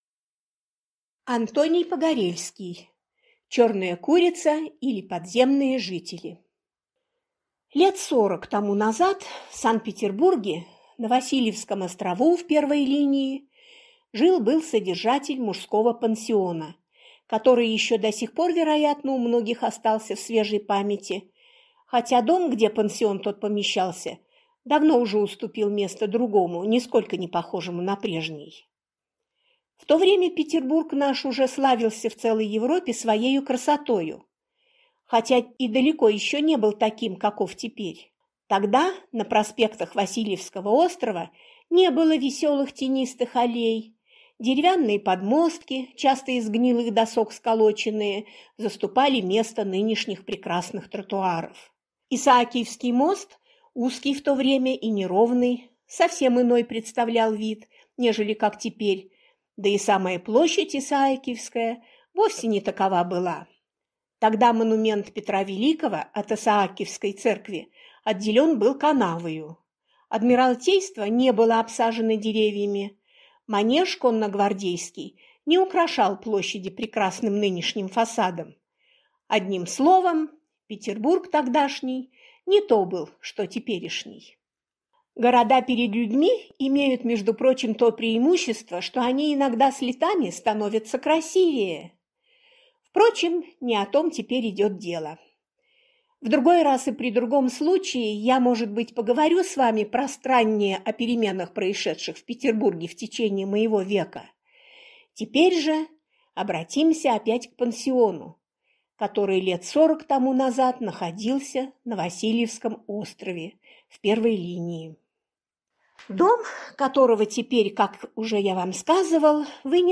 Аудиокнига Черная курица, или Подземные жители | Библиотека аудиокниг